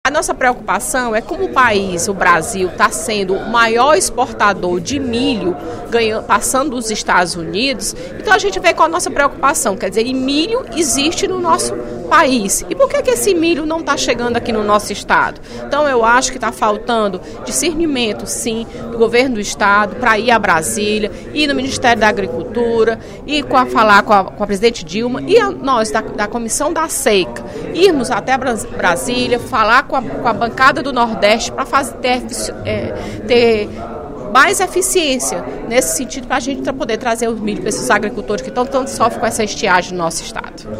A deputada Fernanda Pessoa (PR) reforçou, durante o primeiro expediente da sessão plenária desta quinta-feira (21/03), sua sugestão para que a Comissão da Seca da Assembleia Legislativa reúna-se com a bancada do Nordeste em Brasília.